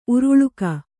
♪ uruḷuka